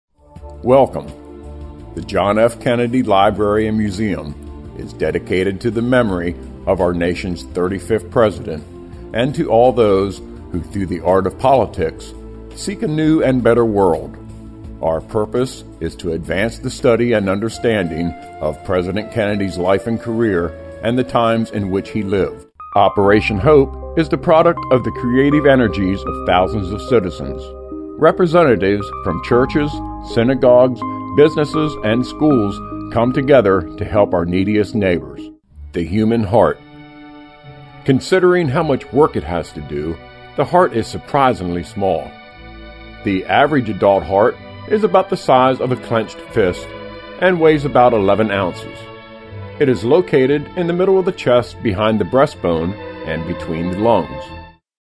Strong yet pleasing voice, warm, humorous, sincere, professional, believable.
Smooth,warm voice for narration.
Sprechprobe: Industrie (Muttersprache):